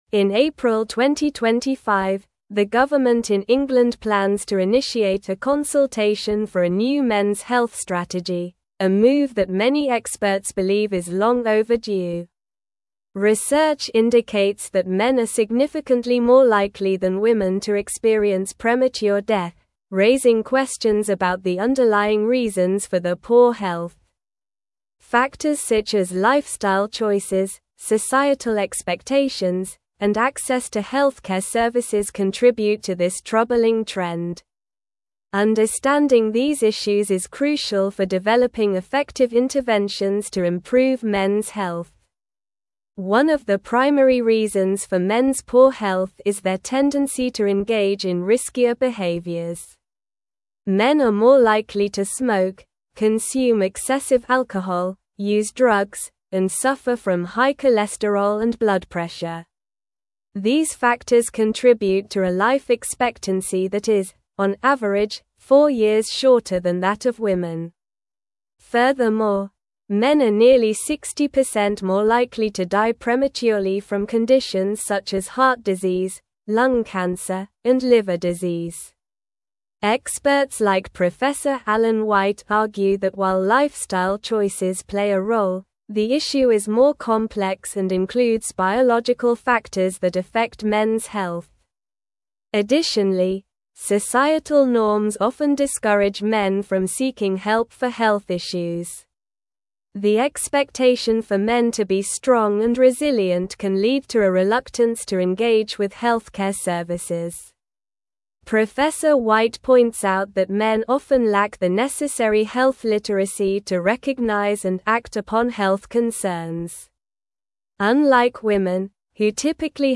Slow
English-Newsroom-Advanced-SLOW-Reading-UK-Government-Launches-Consultation-for-Mens-Health-Strategy.mp3